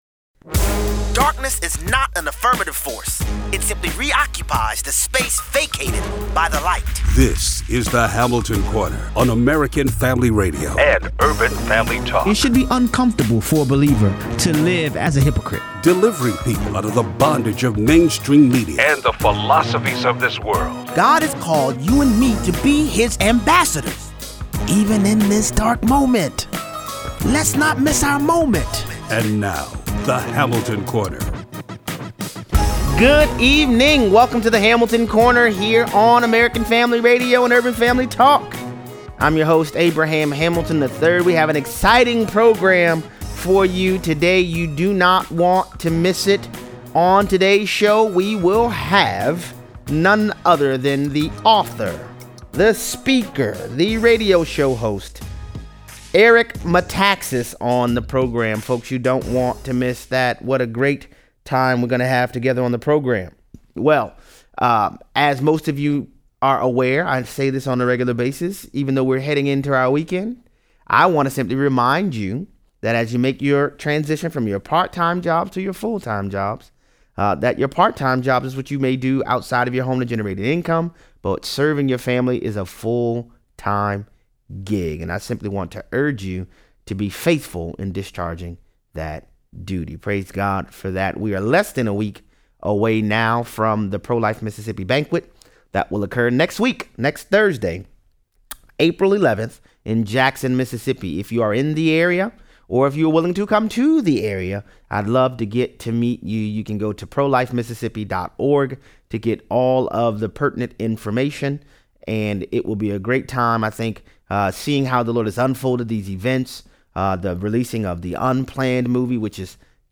Praise God for manna, but we were called to the Promised Land. 0:18-0:35. Author, speaker, and radio show host Eric Metaxas steps into “The Corner.”